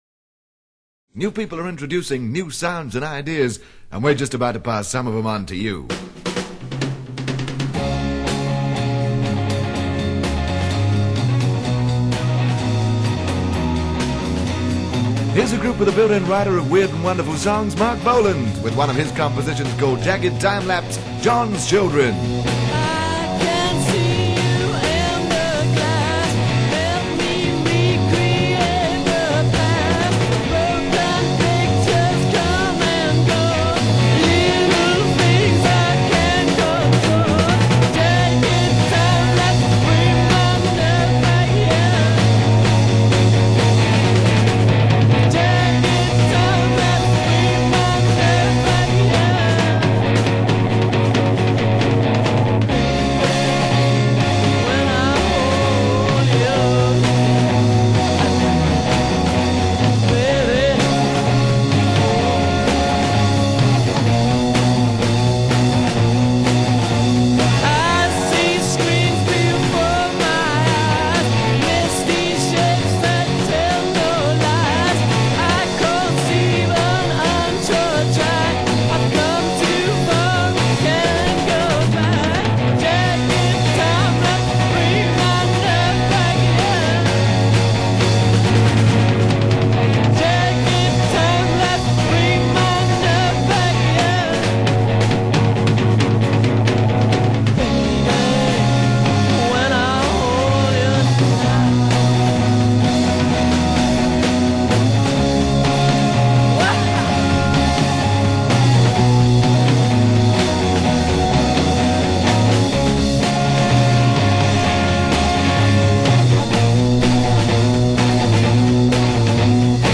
Psychedelia